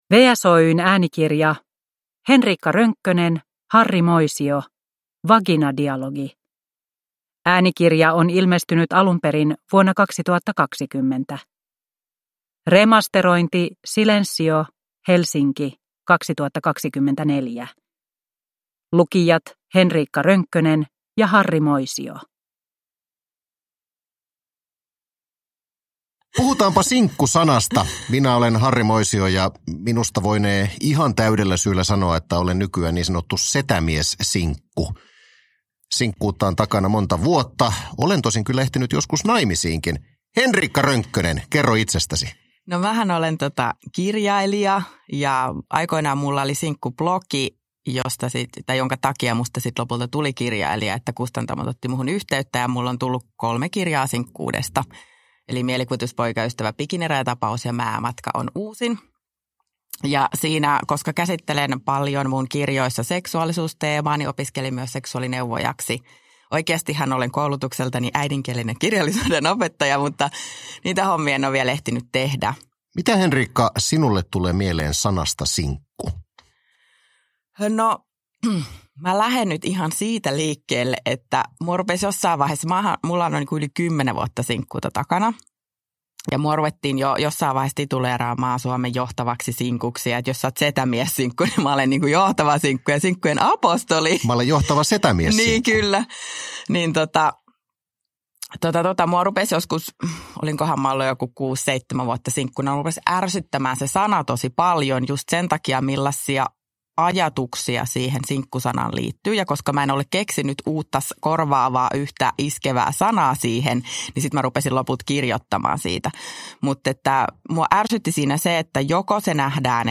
Vaginadialogi – Ljudbok
• Ljudbok